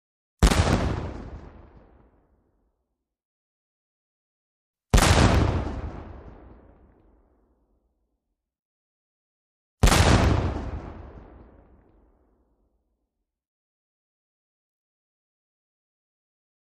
Large Single Cannon Fire ( 3x ); Three Separate Cannon Fires. Huge, Low Pitched Blasts With Some Echo. Close Up Perspective.